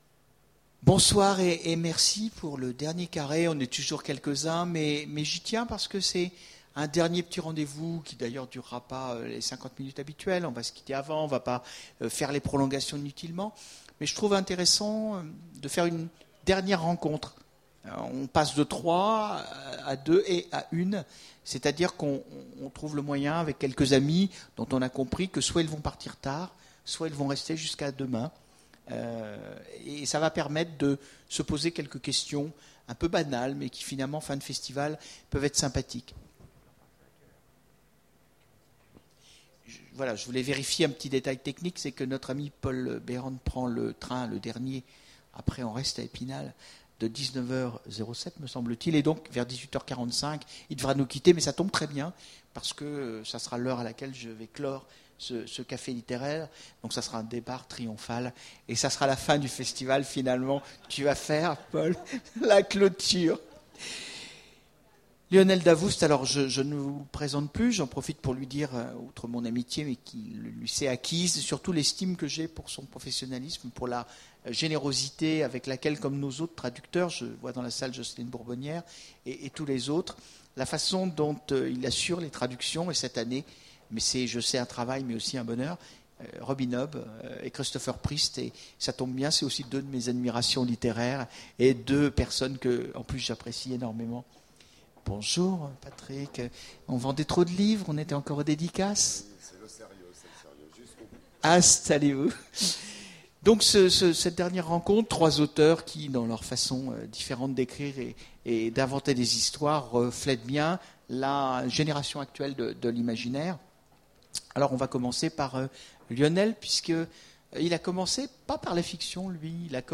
Imaginales 2015 : Conférence Un dernier pour la route !